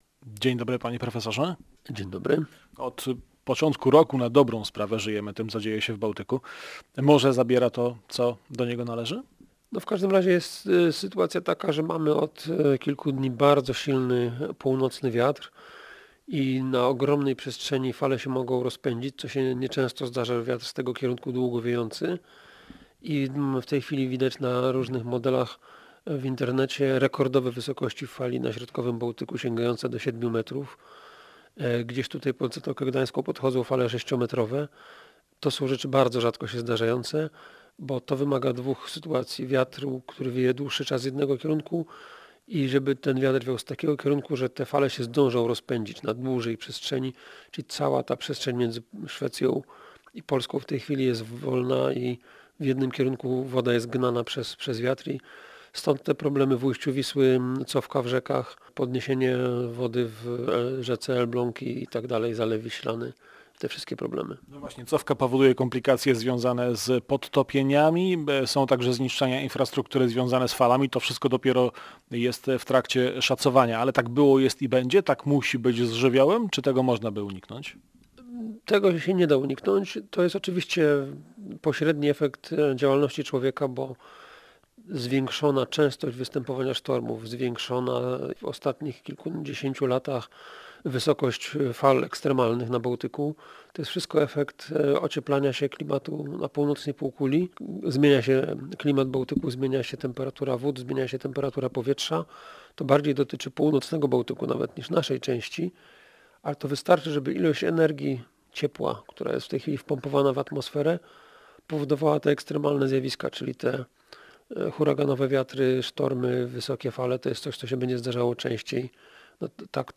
Całej rozmowy
z ekspertem można posłuchać tutaj: